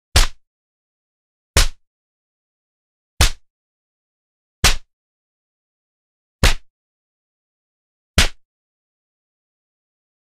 Звук пощечины
Пощечины на допросе:
poschechiny-na-doprose.mp3